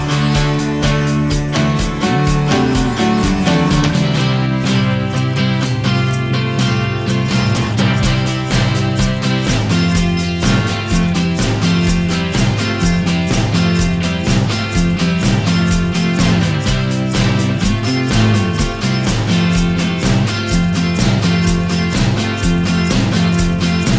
No Backing Vocals Rock 3:12 Buy £1.50